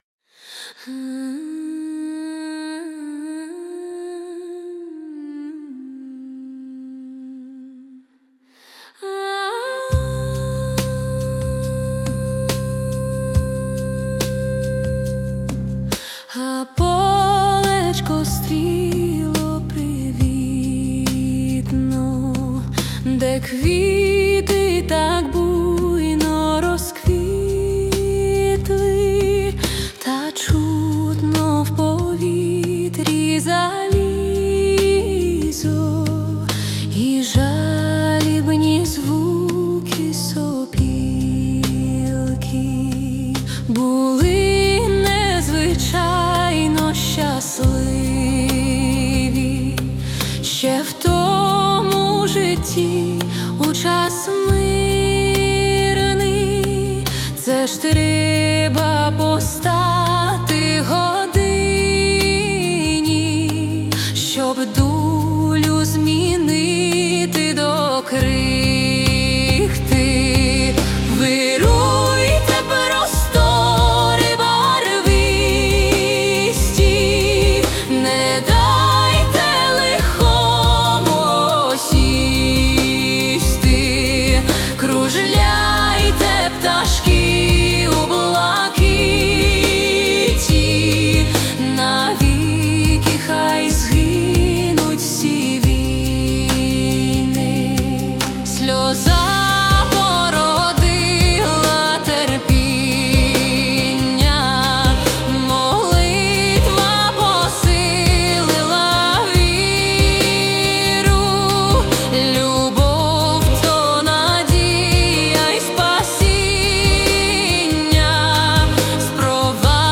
Музична композиція створена за допомогою SUNO AI
Дуже зворушлива, щемно-душевна пісня! 12 16 give_rose 22 22